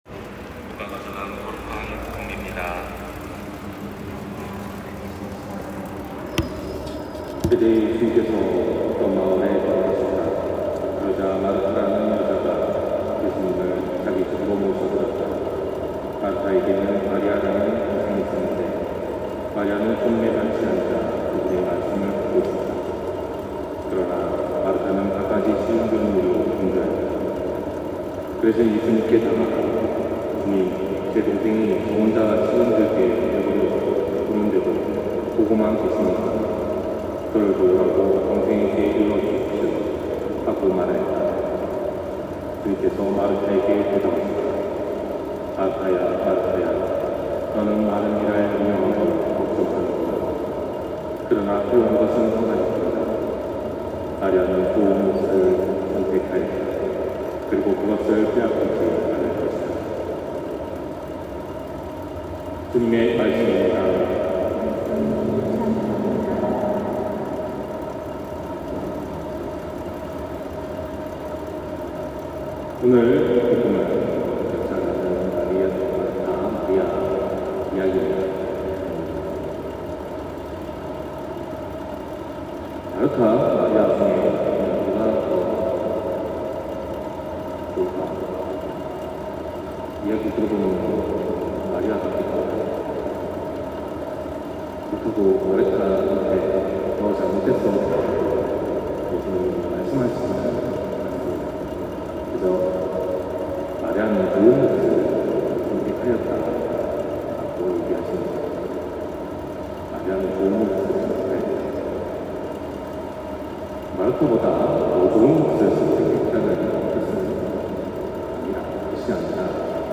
250719신부님강론말씀